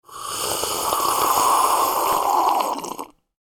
Slurping-sound-effect.mp3